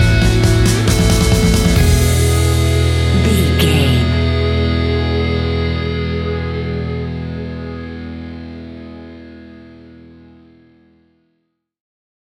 Ninetees Classic Punk Rock Music Stinger.
Fast paced
Ionian/Major
hard rock
instrumentals
Rock Bass
heavy drums
distorted guitars